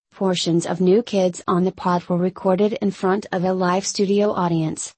Download Live Studio Audience sound effect for free.
Live Studio Audience